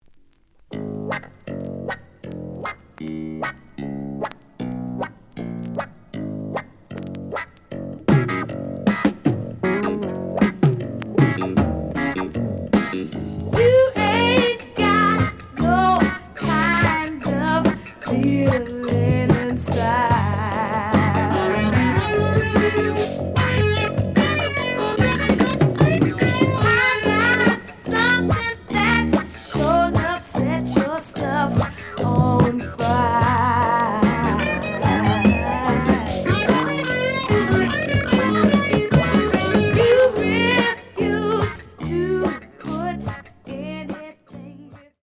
The audio sample is recorded from the actual item.
●Genre: Funk, 70's Funk
Slight noise on both sides.)